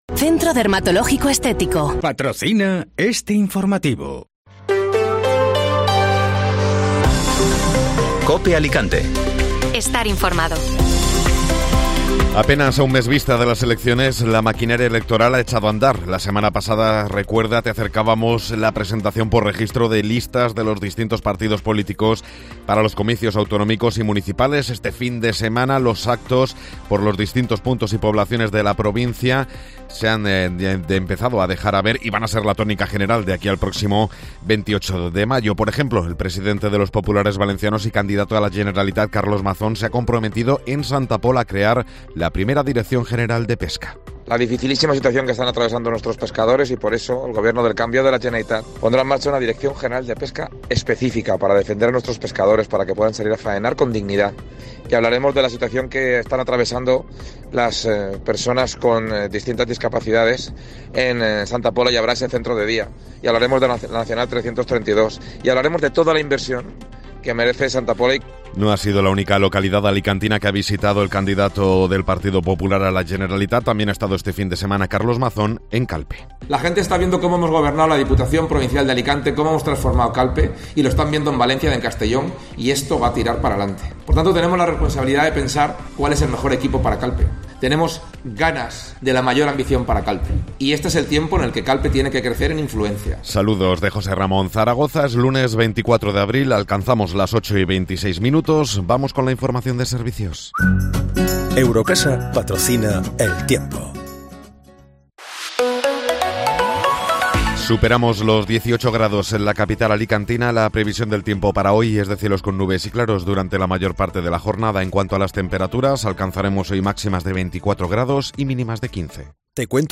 Informativo Matinal (Lunes 24 de Abril)